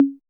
808 CGA MID.wav